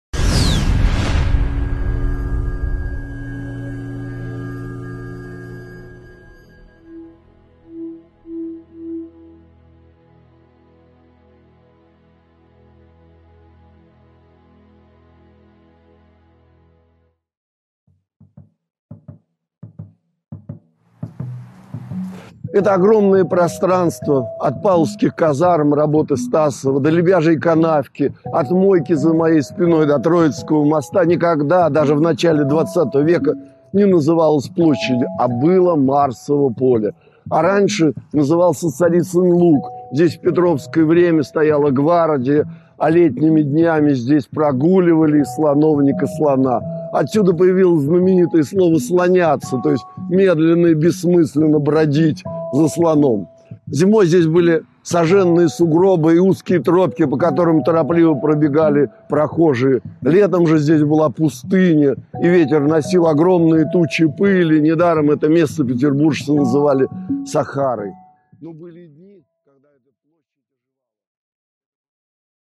Аудиокнига Санкт-Петербург начала XX века. Эпизод 1 | Библиотека аудиокниг